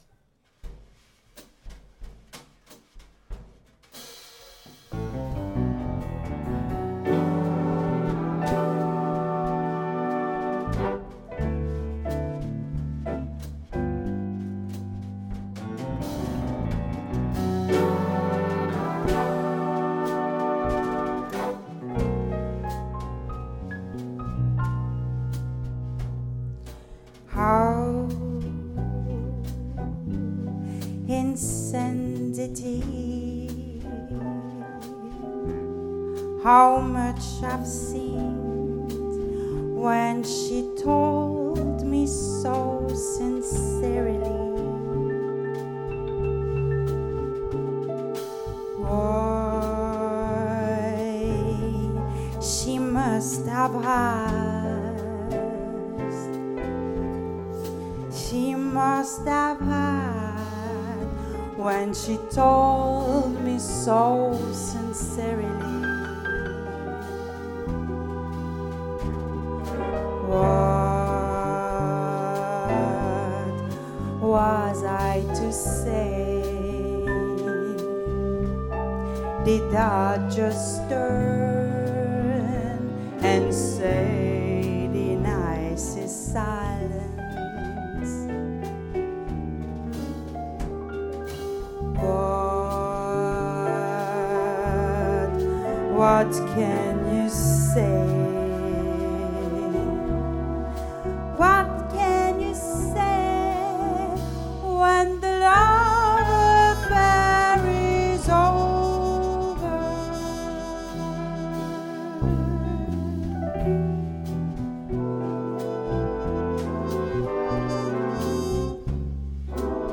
sur la scène du festival en 2024
Insensatez (How Insensitive en anglais) est une chanson brésilienne composée par Antônio Carlos Jobim
Saxophone
Trombone
Trompette
Chant
Piano